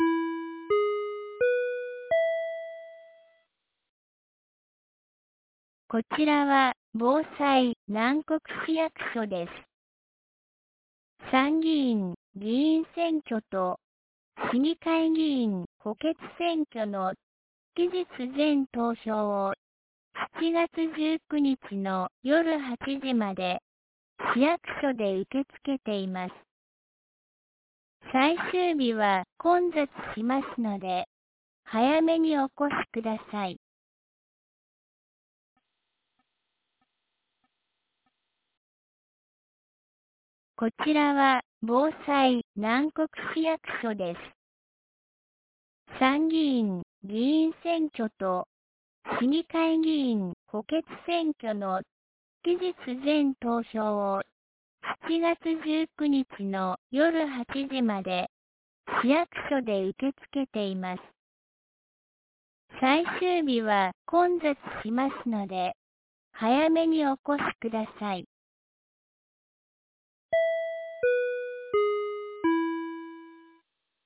2025年07月18日 10時01分に、南国市より放送がありました。